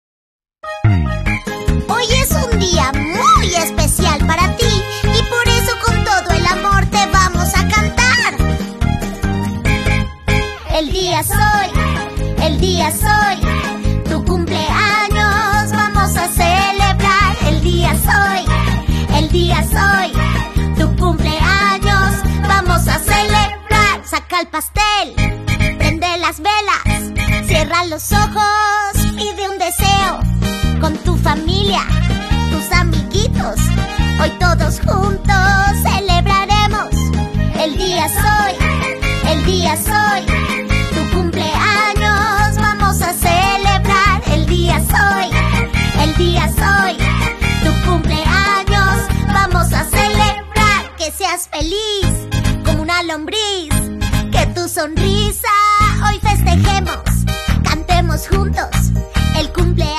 canción de cumpleaños